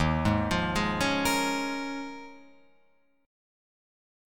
D#9sus4 chord